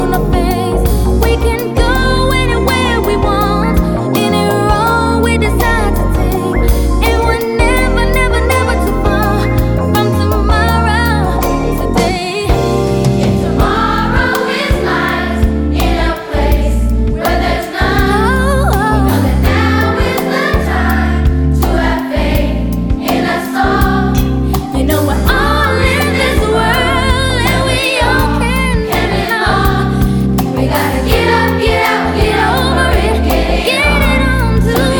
Жанр: Поп / R&b / Соул